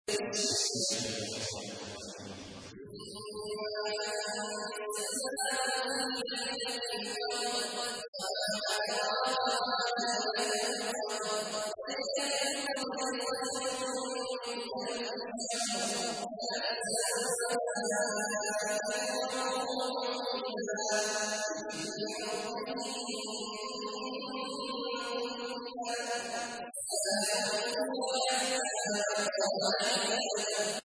تحميل : 97. سورة القدر / القارئ عبد الله عواد الجهني / القرآن الكريم / موقع يا حسين